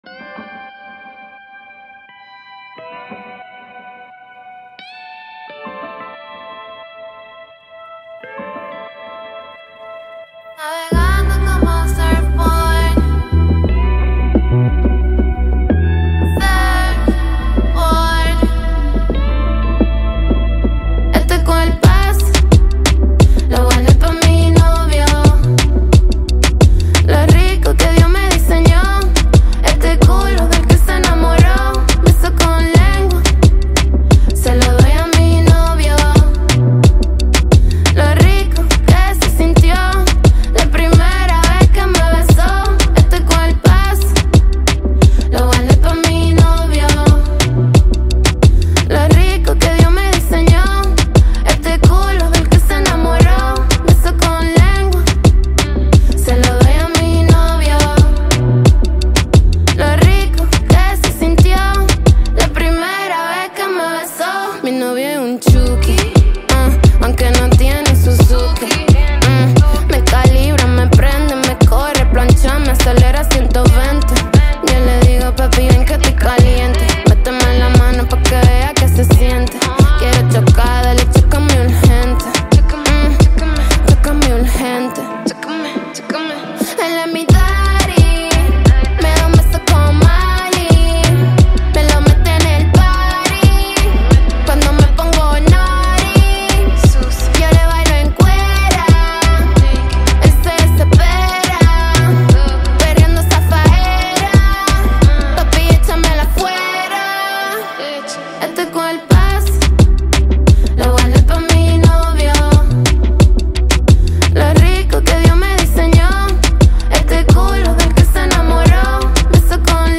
It keeps a steady pace that never feels overwhelming.